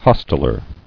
[hos·tel·er]